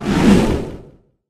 gus_atk_02.ogg